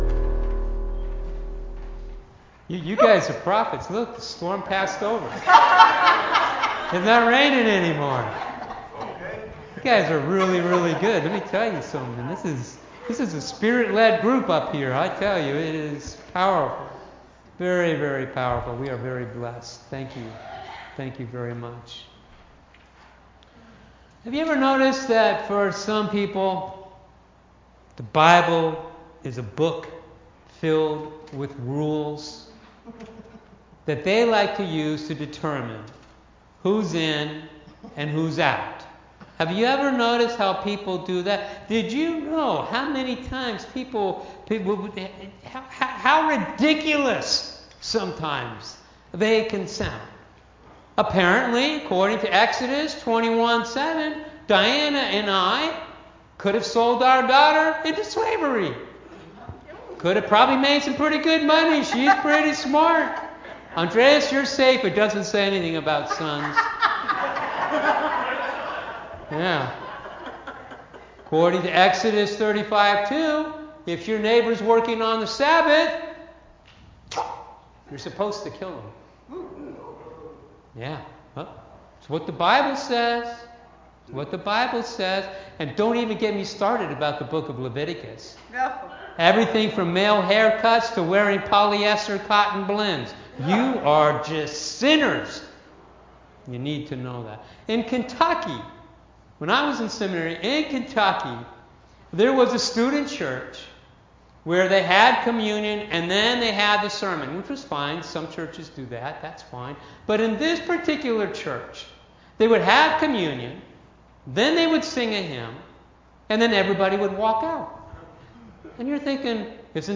Online Sunday Service